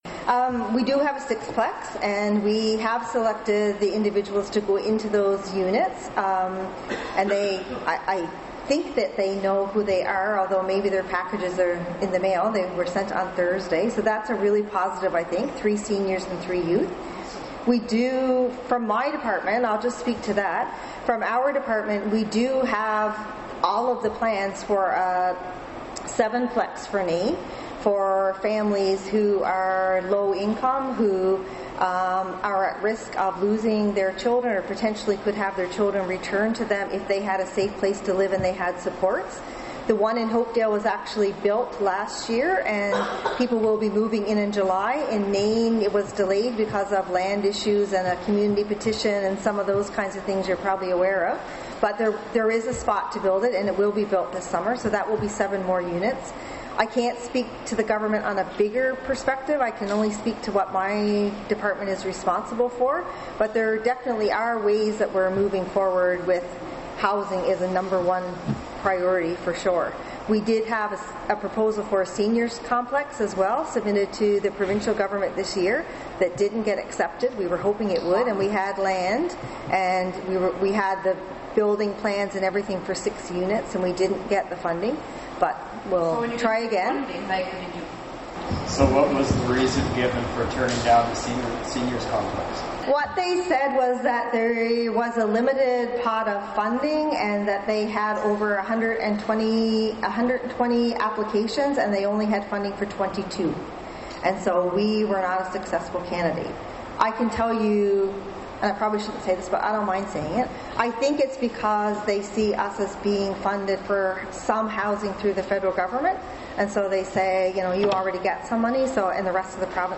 Labrador-Grenfell Health and the Nunatsiavut Government’s Department of Health and Social Development (DHSD) held a community meeting last Thursday June 7, 2018 at 3 pm in the afternoon in the Jeremias Sillett Community Centre to brief Nain residents on the latest news about the current Tuberculosis (TB) crisis.